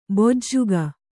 ♪ bojjuga